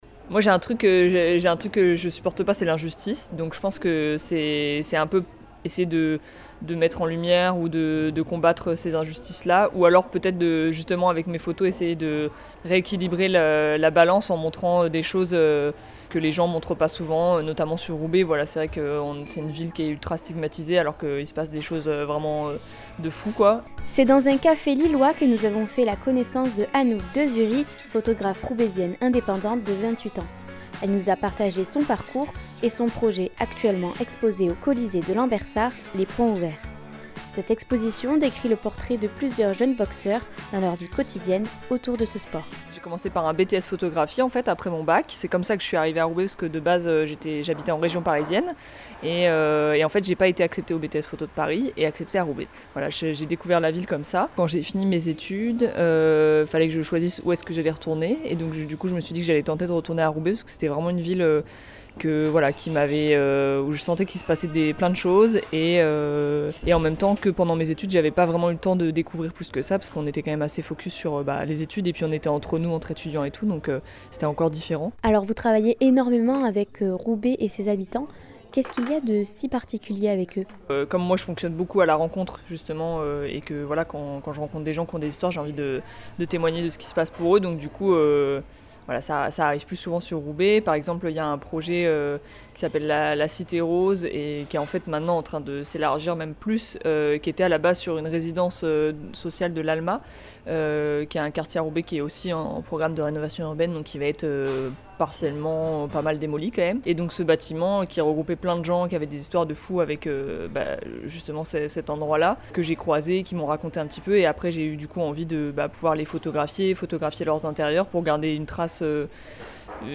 3. REPORTAGES